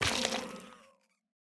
Minecraft Version Minecraft Version 1.21.5 Latest Release | Latest Snapshot 1.21.5 / assets / minecraft / sounds / block / sculk / break5.ogg Compare With Compare With Latest Release | Latest Snapshot